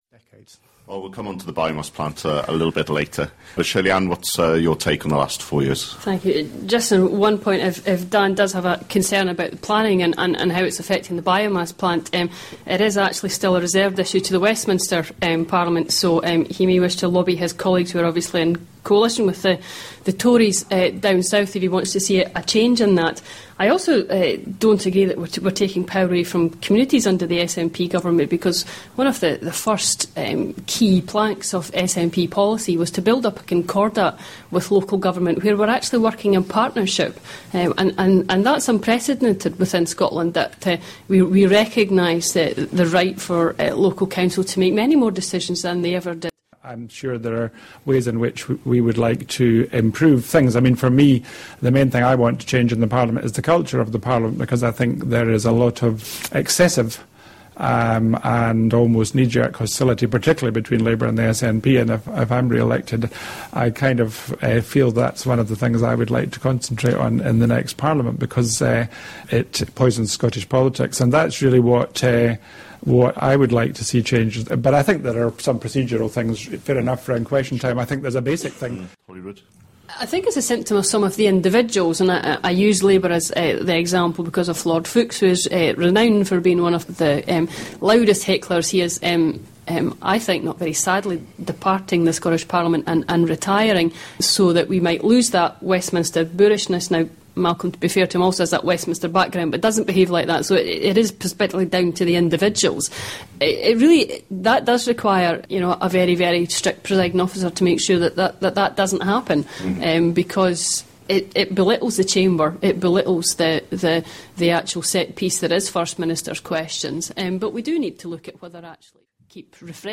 Highlights of Scottish Parliament debate for Leith